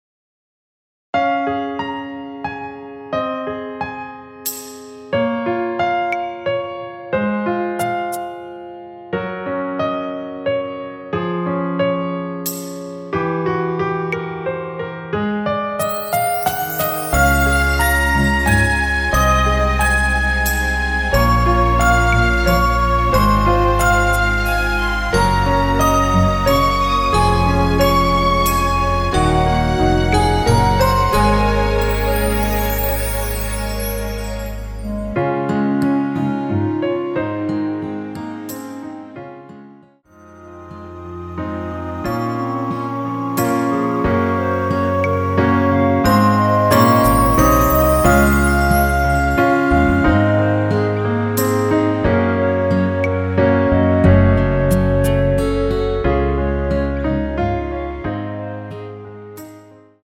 원키에서(-1)내린 멜로디 포함된 MR입니다.
Db
앞부분30초, 뒷부분30초씩 편집해서 올려 드리고 있습니다.
중간에 음이 끈어지고 다시 나오는 이유는